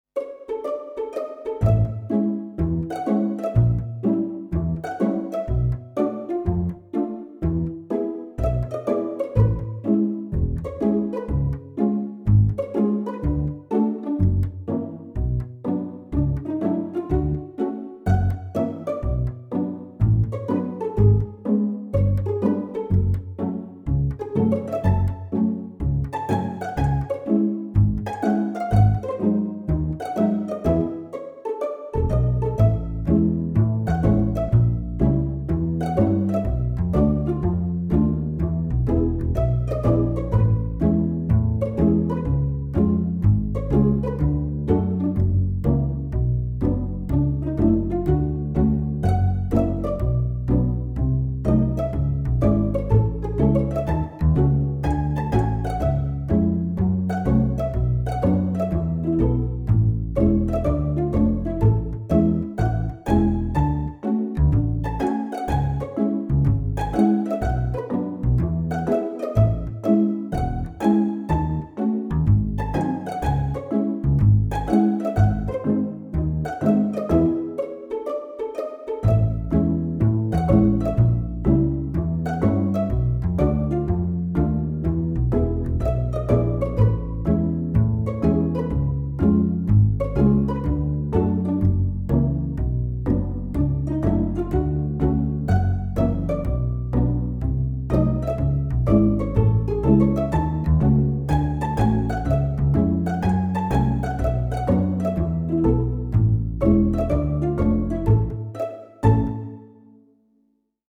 (middle 8 added!)